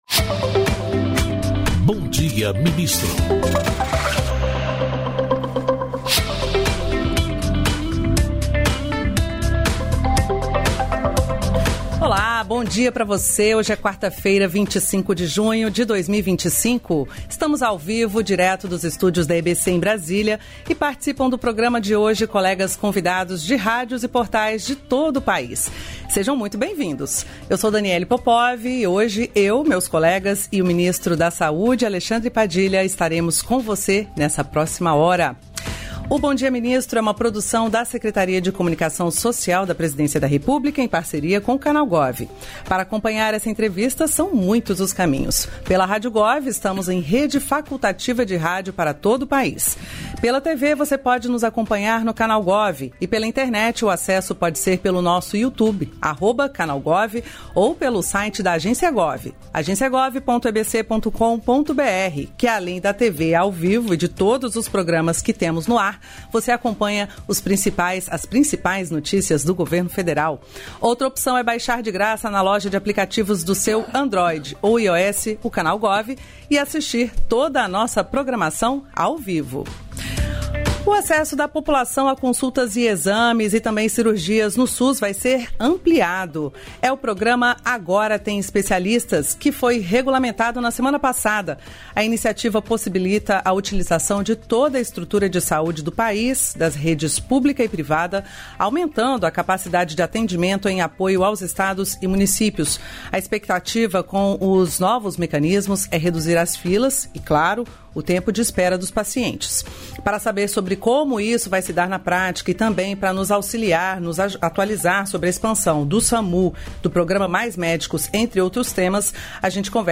Íntegra da participação do ministro da Saúde, Alexandre Padilha, no programa "Bom Dia, Ministro" desta quarta-feira (25), nos estúdios da EBC em Brasília (DF).